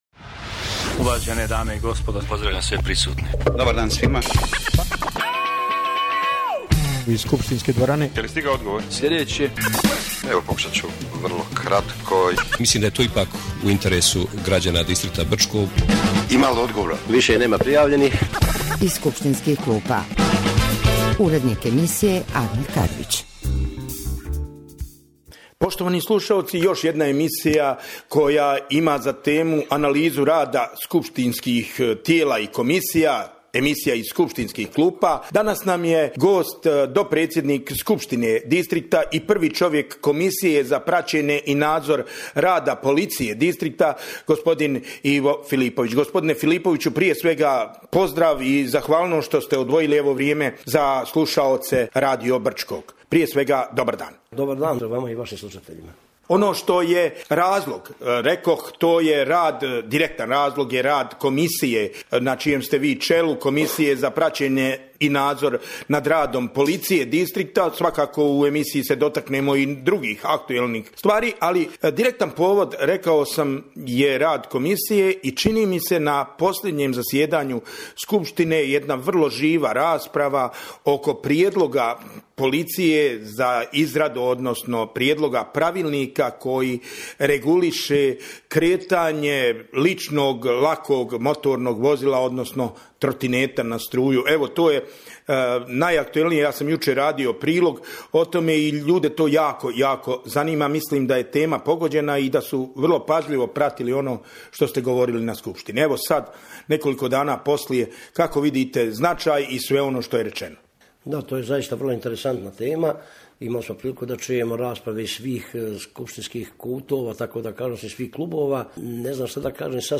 U emisiji Iz skupštinskih klupa gost Ivo Filipović, dopredsjednik Skupštine Brčko distrikta BiH